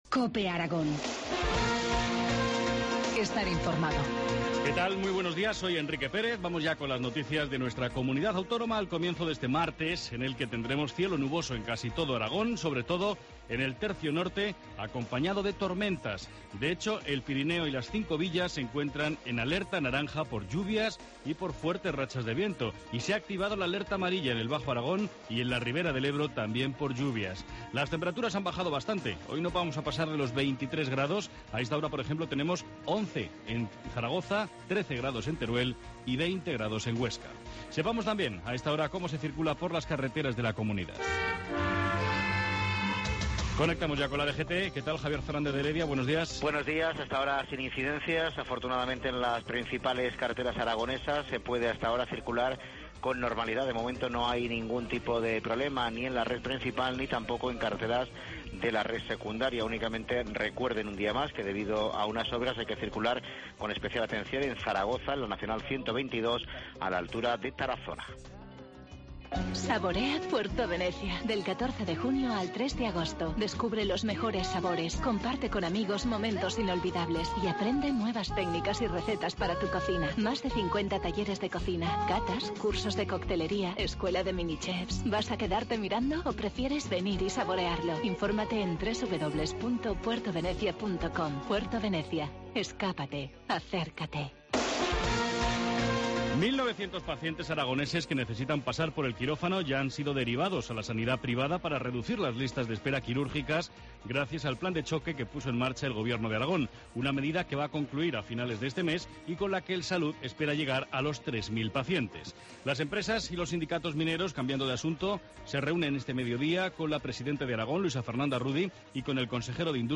Informativo matinal, martes 18 de junio, 7.25 horas